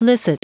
Prononciation du mot licit en anglais (fichier audio)
Prononciation du mot : licit